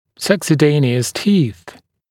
[ˌsʌksə’deɪnɪəs tiːθ][ˌсаксэ’дэйниэс ти:с]постоянные зубы, следующие за молочными